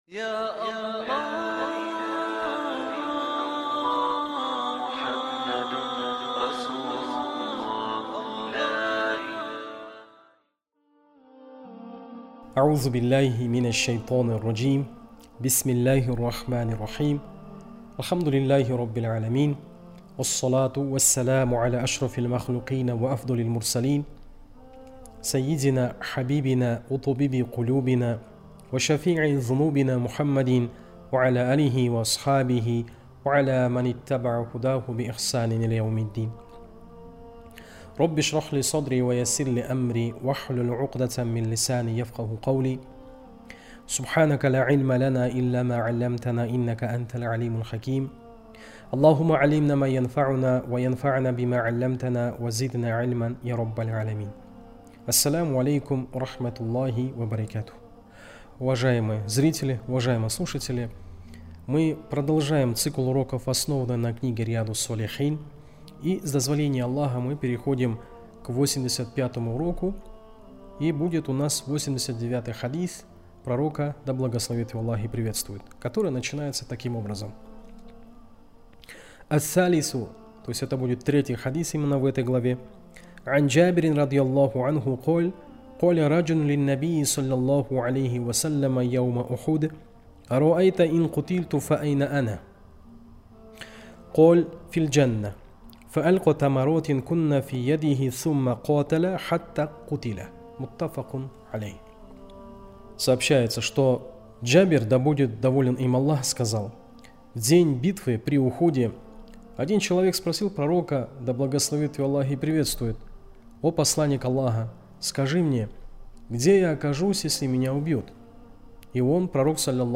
Урок 85.